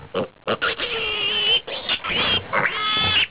Pig
PIG.wav